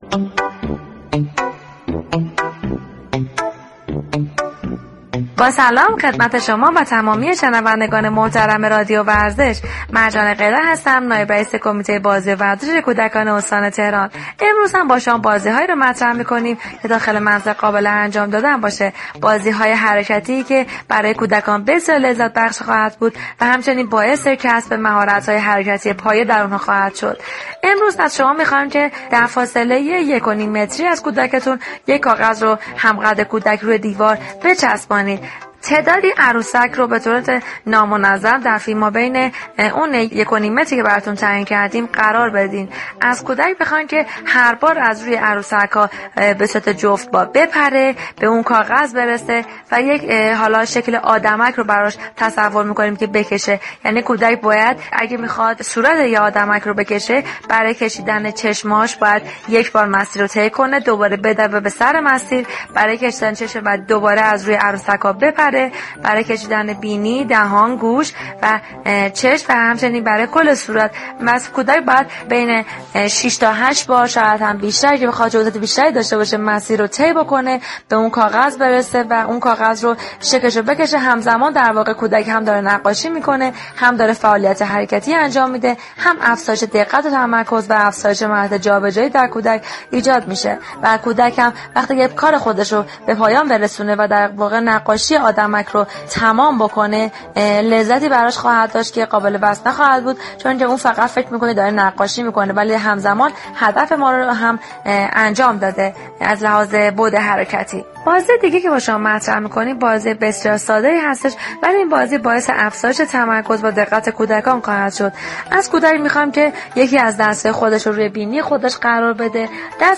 شما می توانید از طریق فایل صوتی ذیل شنونده بخشی از برنامه "گلخونه" رادیو ورزش كه به توضیح درباره نحوه اجرای این بازی می پردازد؛ باشید.